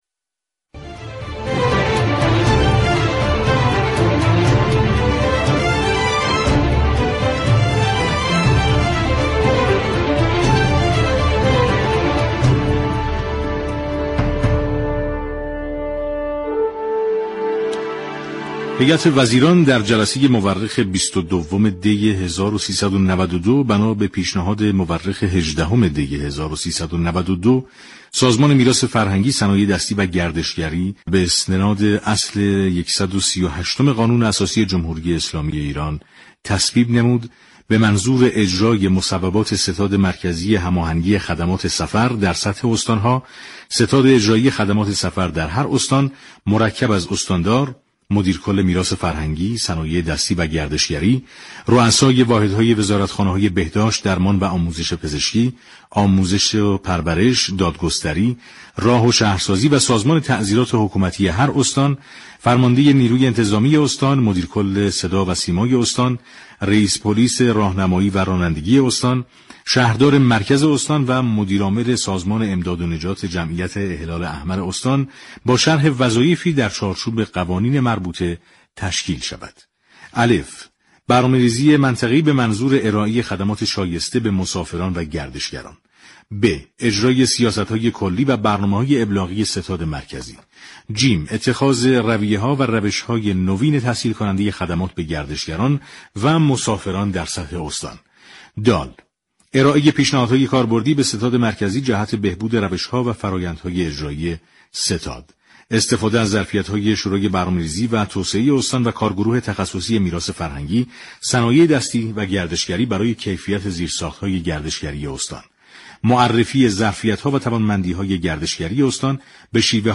در گفت و گو با برنامه پل مدیریت رادیو تهران